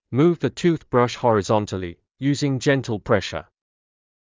ﾑｰﾌﾞ ｻﾞ ﾄｩｰｽﾌﾞﾗｯｼ ﾎﾘｿﾞﾝﾀﾘｲ ﾕｰｼﾞﾝｸﾞ ｼﾞｪﾝﾄﾙ ﾌﾟﾚｯｼｬｰ